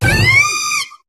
Cri de Roussil dans Pokémon HOME.